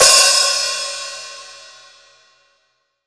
Index of /90_sSampleCDs/AKAI S6000 CD-ROM - Volume 3/Hi-Hat/STUDIO_HI_HAT